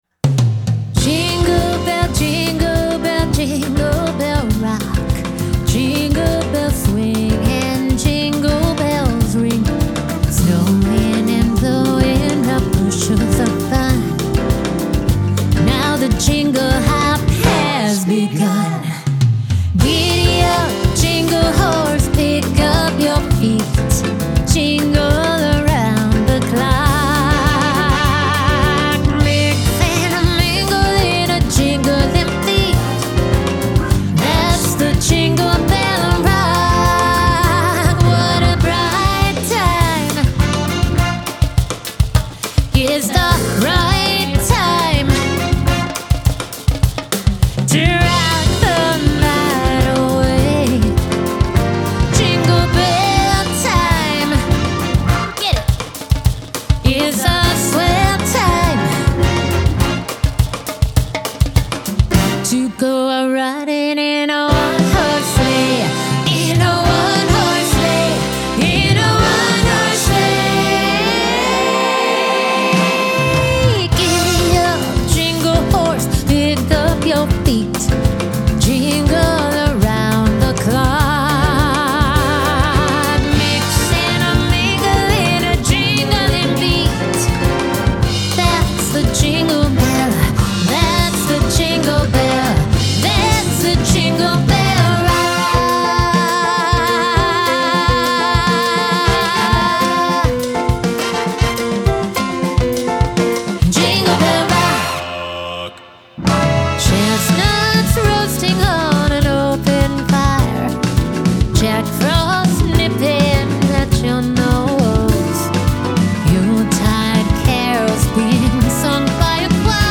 Genre : Christmas Music